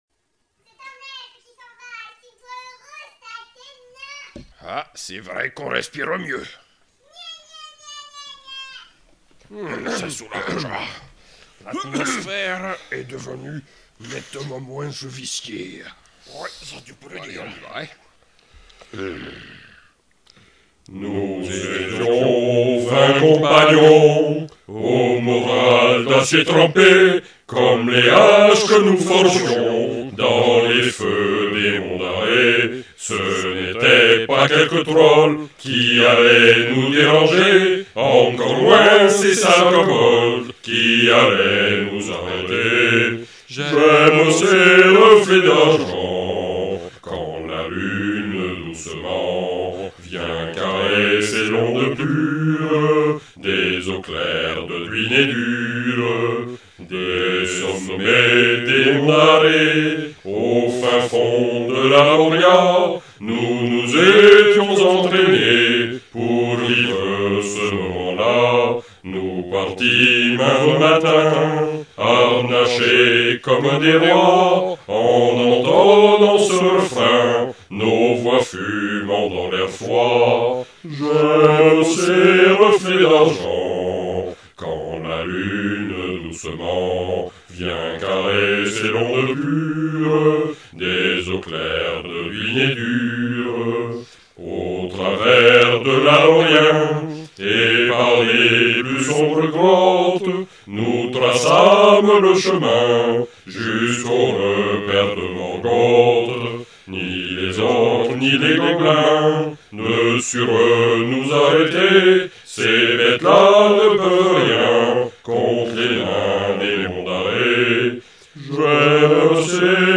chant de nains.